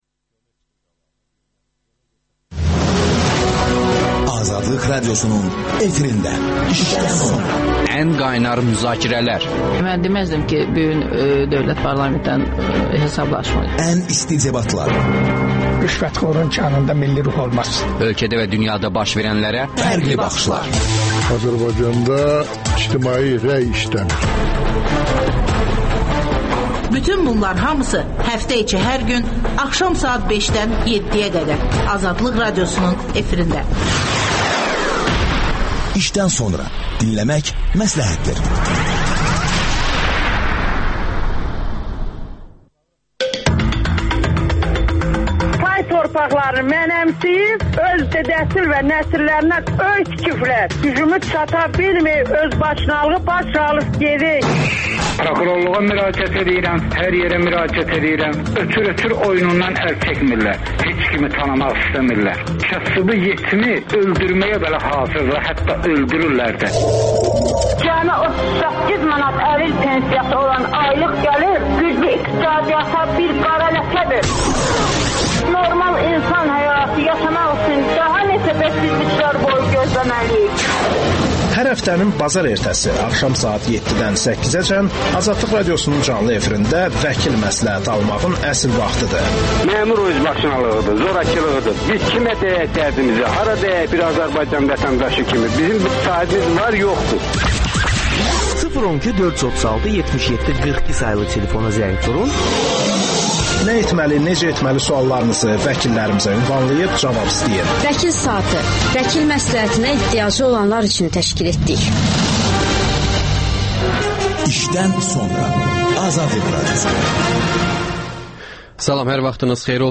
Azərbaycan hökuməti Qərbi nədə ittiham edir? Qərb həqiqətən Azərbaycanda hakimiyyət dəyişikliyinə çalışırmı? Politoloqlar